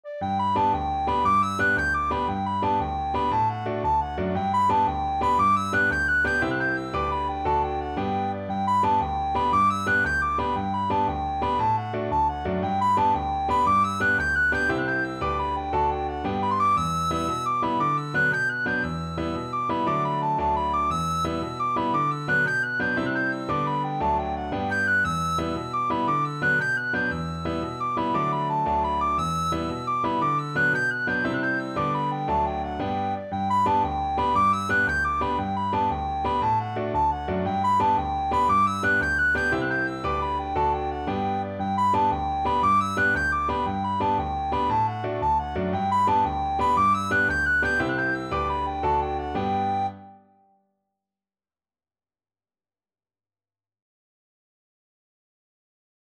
Free Sheet music for Soprano (Descant) Recorder
6/8 (View more 6/8 Music)
D6-G7
G major (Sounding Pitch) (View more G major Music for Recorder )
With energy .=c.116
Irish